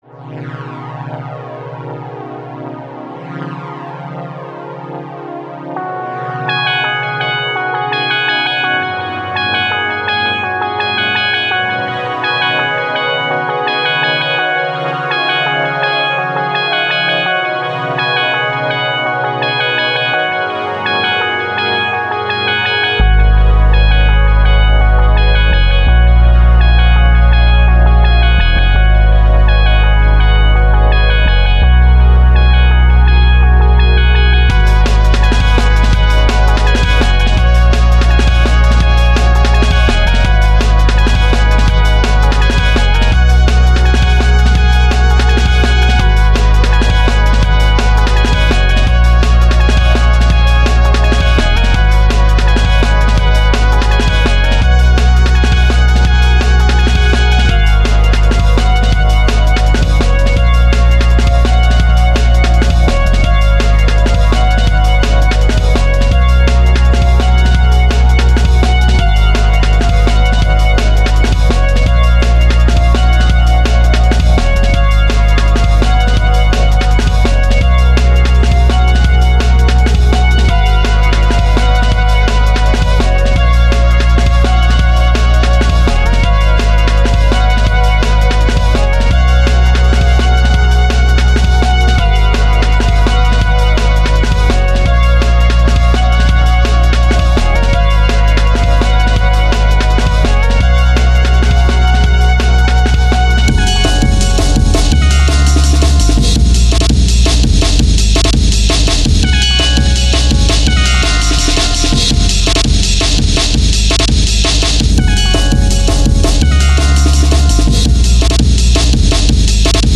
Funky Very Happy
But it wouldn't suit CNC:Rather suits a arcade game.